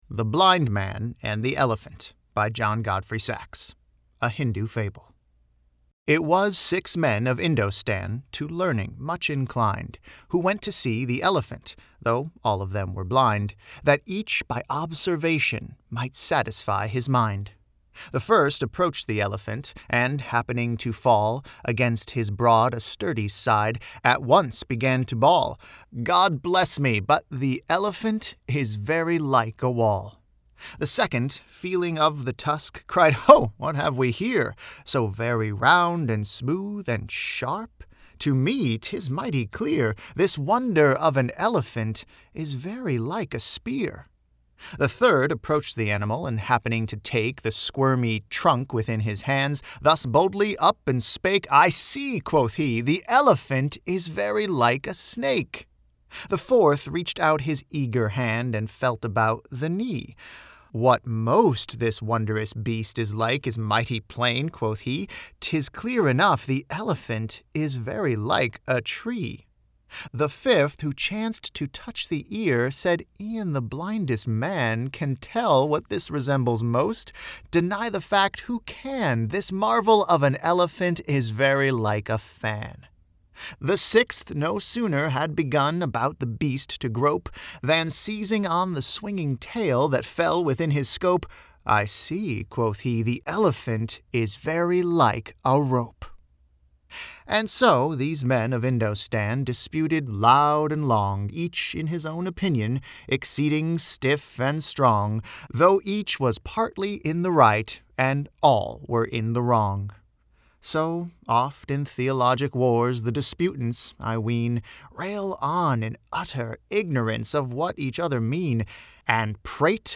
I'm going to play a recording of a poem for you.